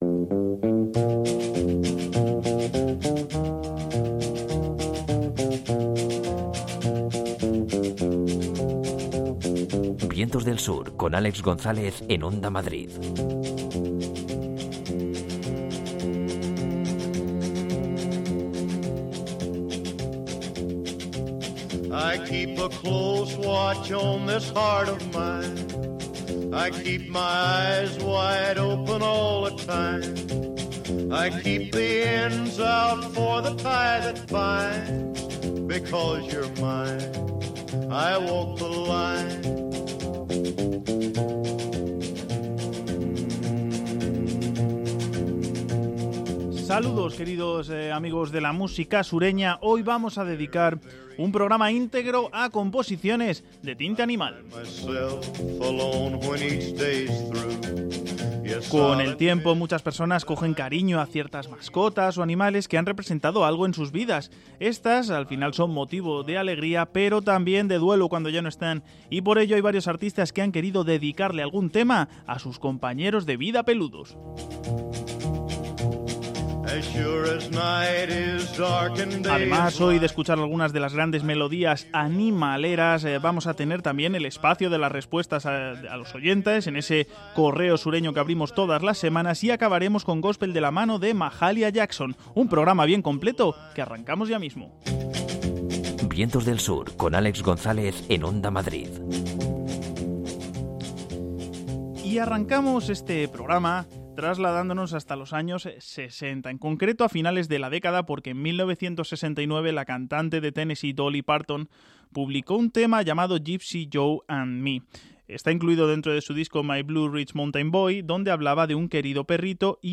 hace un repaso por canciones donde de una forma u otra los animales son protagonistas de temas de música country.